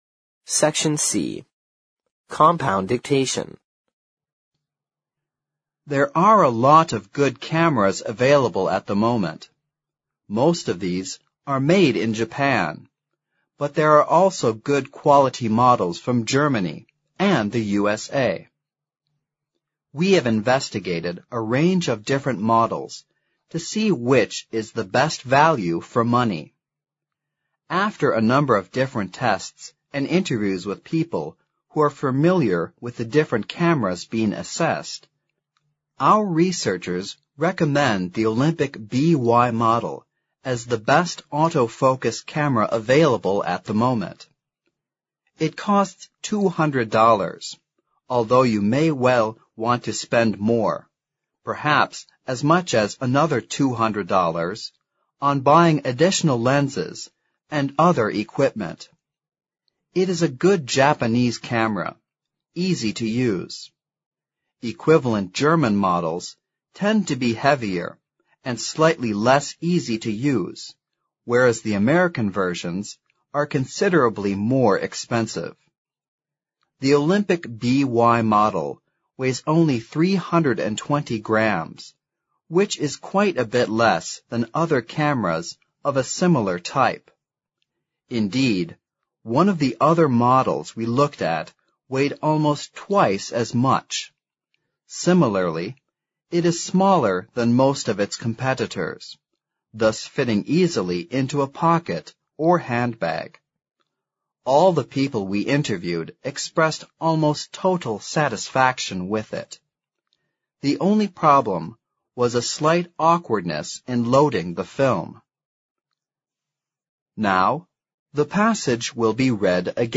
Section C Compound Dictation